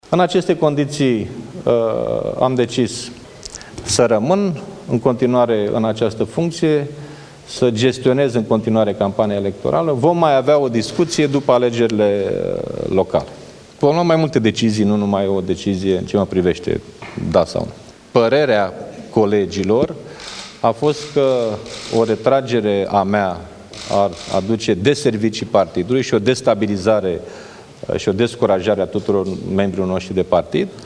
Dragnea a declarat la finalul şedinţei Comitetului Executiv Naţional că partidul a decis ”aproape” în unanimitate ca el să rămână la conducerea social-democraţilor pentru a gestiona campania electorală: